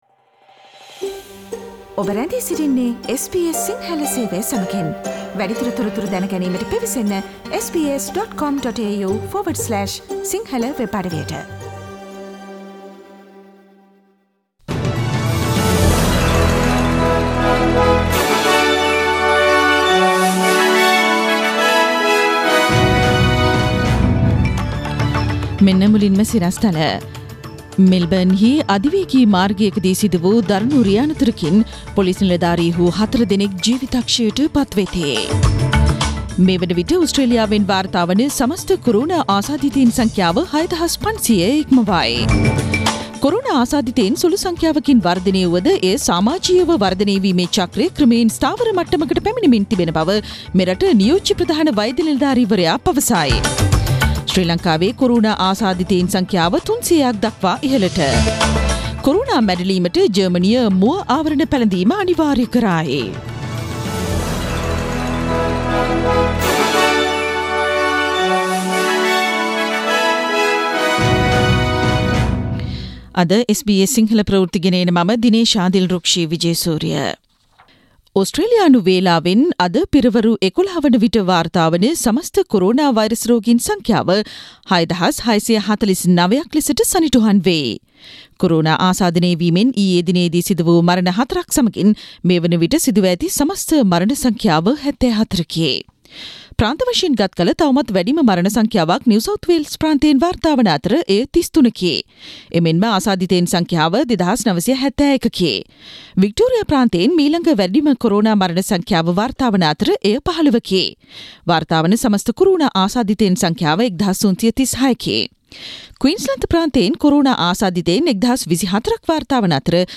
Daily News bulletin of SBS Sinhala Service: Thursday 23 April 2020
Today’s news bulletin of SBS Sinhala radio –Thursday 23 April 2020.